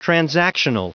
Prononciation du mot : transactional